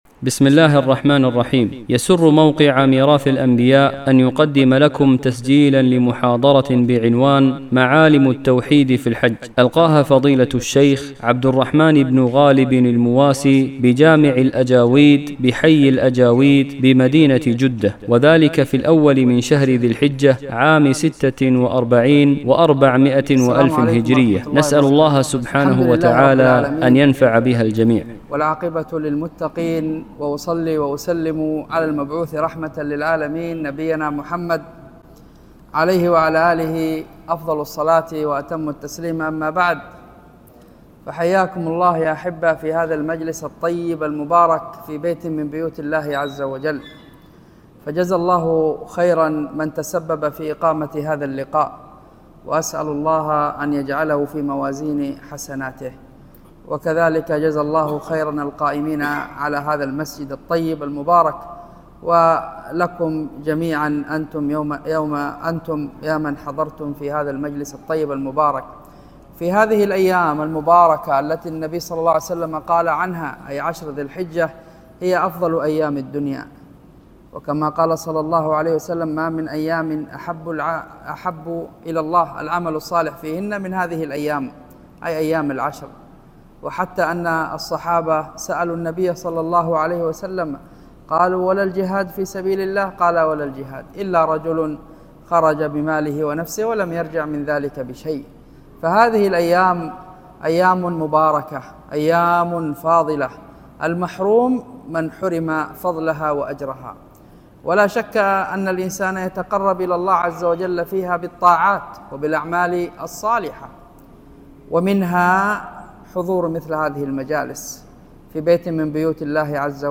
محاضرة
محاضرة-معالم-في-التوحيد.mp3